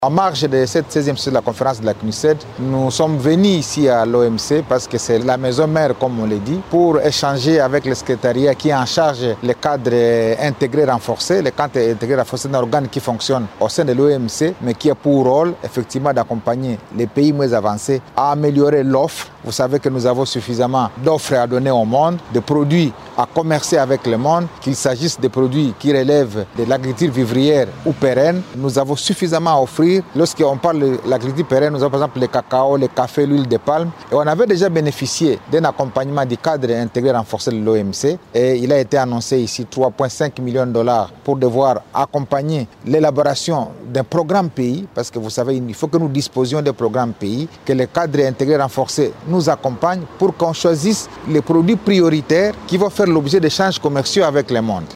Lors de la réunion des ministres africains du Commerce tenue le mardi 21 octobre à Genève, en marge de la 16ᵉ session de la Conférence des Nations Unies sur le Commerce et le développement (CNUCED), le ministre congolais du Commerce extérieur, Julien Paluku Kahongya, a souligné que la promotion du commerce intra-africain dépendra de la mise en œuvre effective de la Zone de libre-échange continentale africaine (ZLECAF).